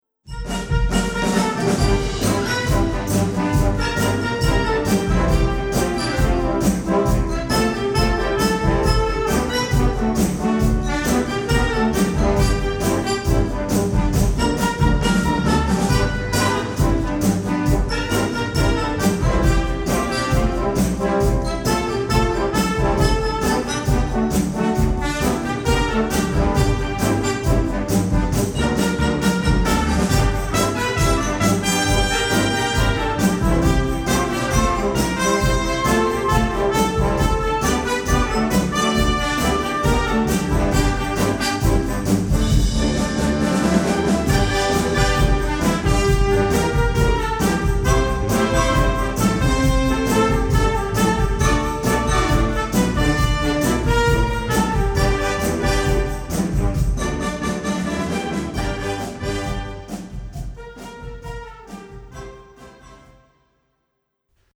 Recueil pour Harmonie/fanfare - Marching Band